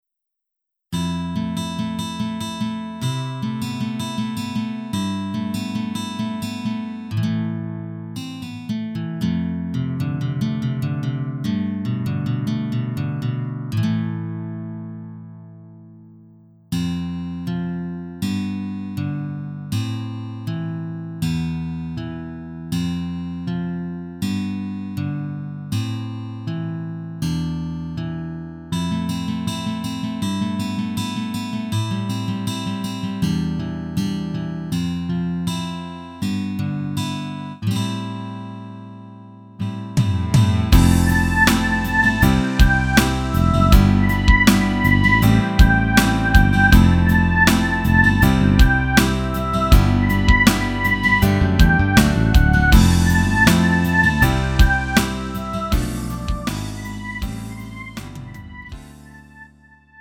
음정 원키 3:39
장르 구분 Lite MR